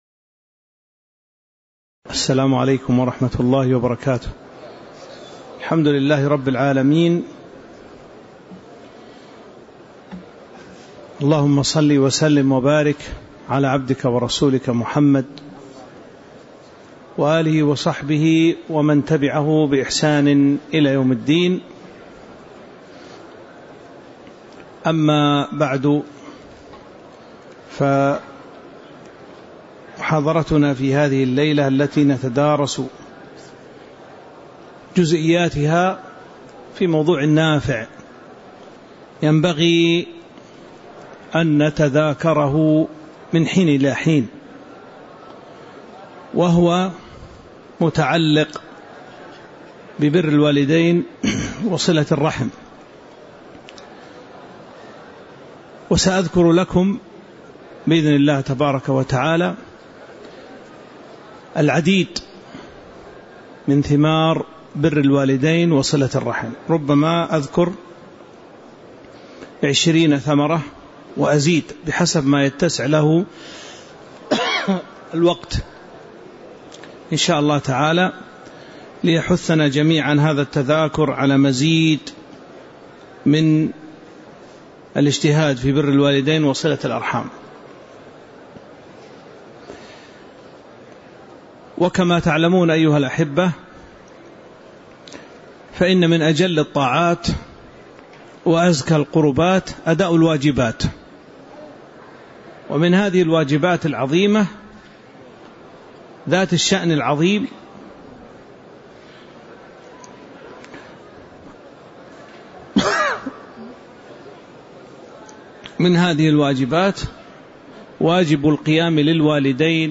تاريخ النشر ٣٠ جمادى الآخرة ١٤٤٦ هـ المكان: المسجد النبوي الشيخ